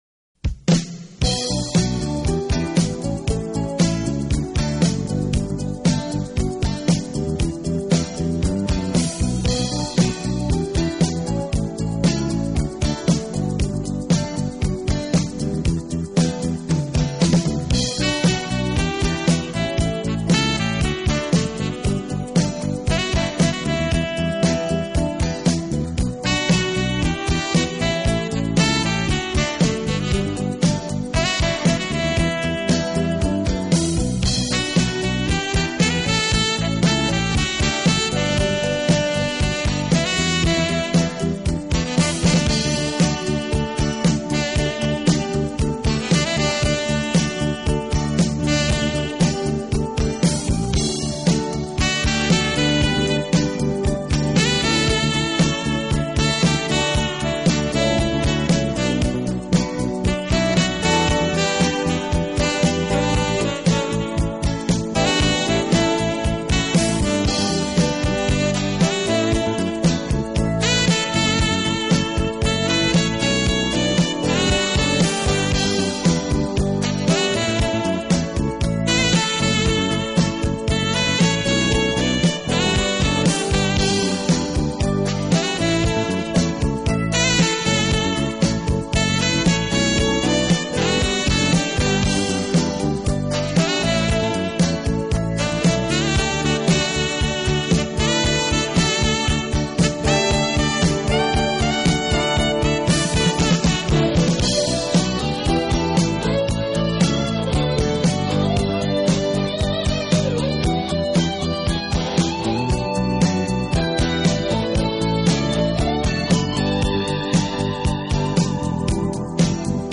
Genre: Jazz Styles: New Age, World